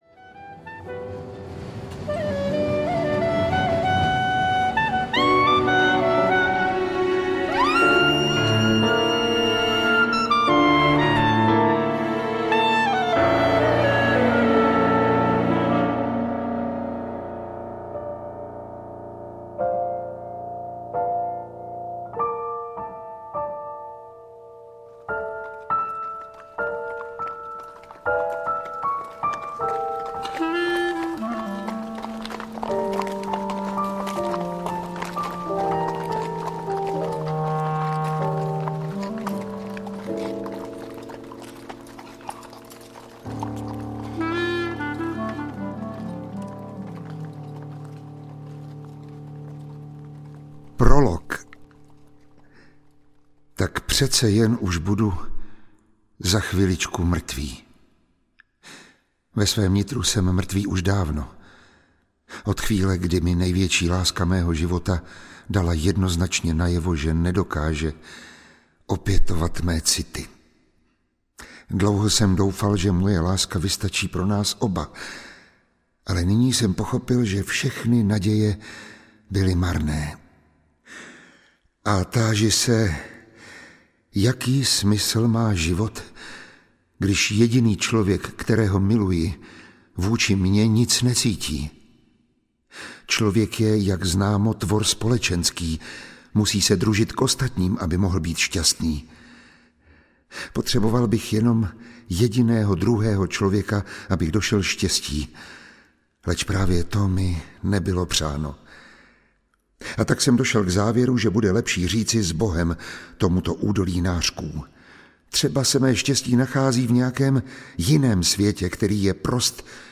1913 - Případ podivné sebevraždy audiokniha
Ukázka z knihy